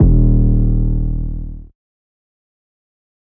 REDD 808 (9).wav